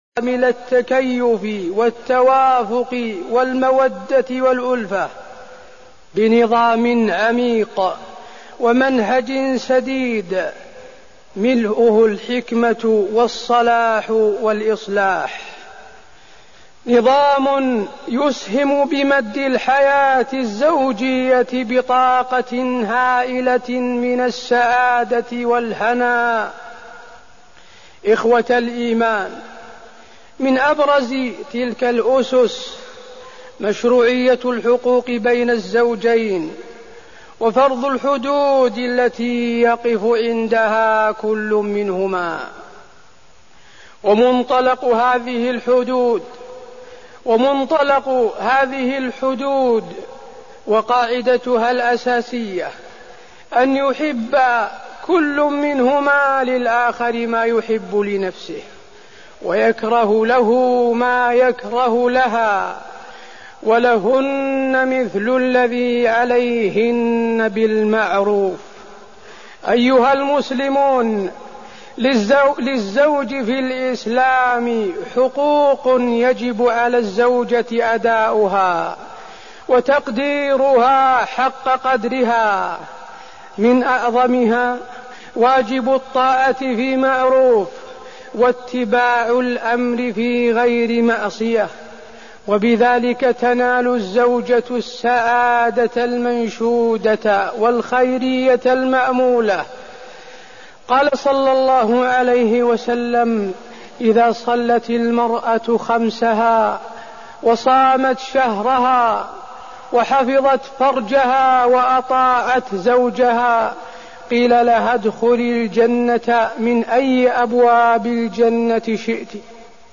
خطبة المحبة والمودة بين الزوجين وفيها: الحقوق بين الزوجين في الإسلام، والمقصود بطاعة الزوجة زوجها، ودور المرأة في حفظ الحياة الزوجية، ودور الرجل
تاريخ النشر ١٩ جمادى الآخرة ١٤١٩ المكان: المسجد النبوي الشيخ: فضيلة الشيخ د. حسين بن عبدالعزيز آل الشيخ فضيلة الشيخ د. حسين بن عبدالعزيز آل الشيخ المحبة والمودة بين الزوجين